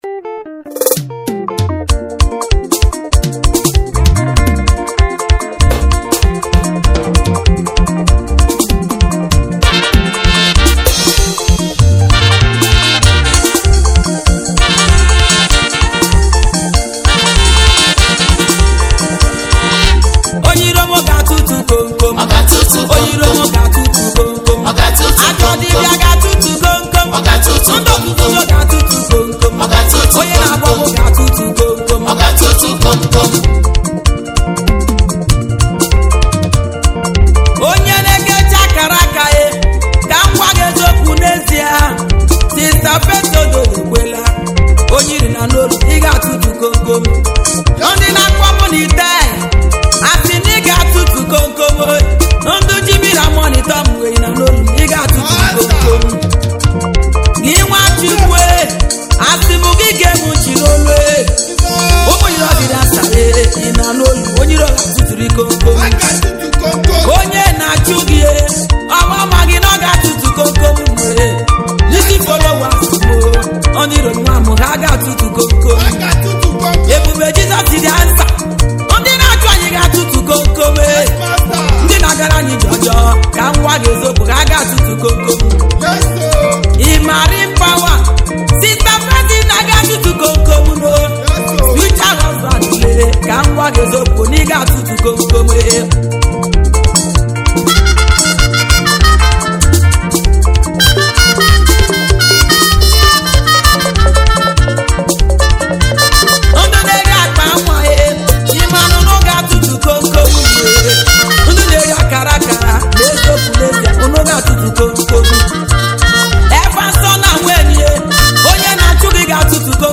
African Praise Music Video